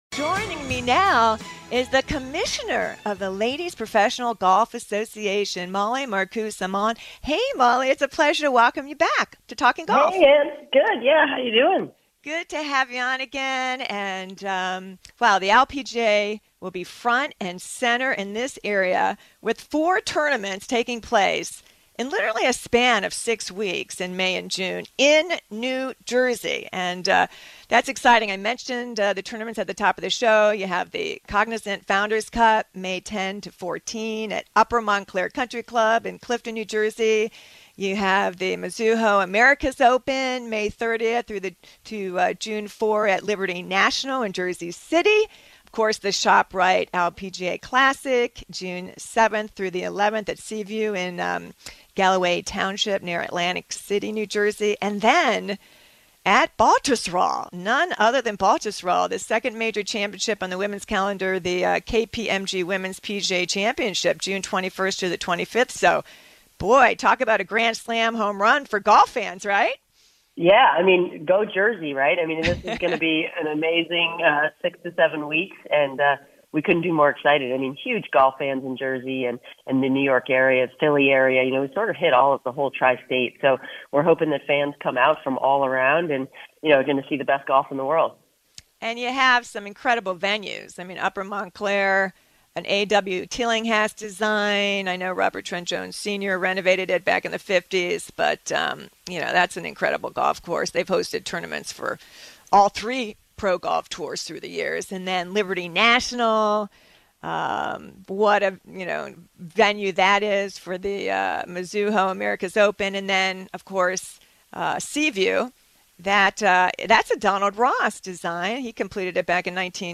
an exclusive interview